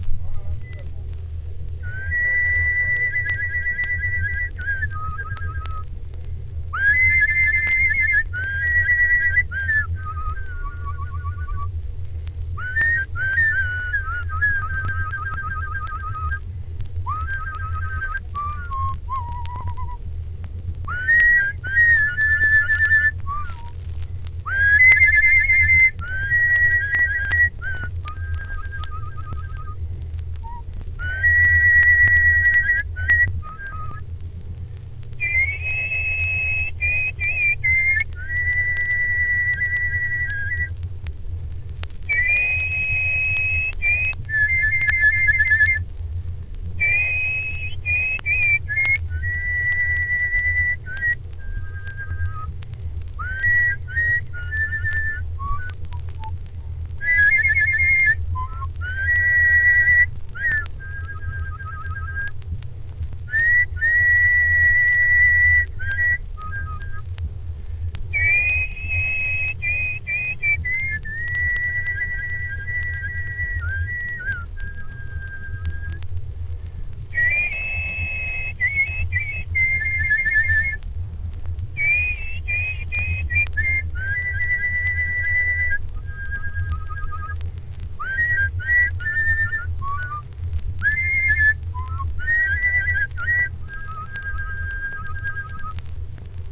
吹奏樂器 (Wind Instruments)
Whistling is a way to use your vocal cords as a musical instrument.
sh_oddball_wind_3.wav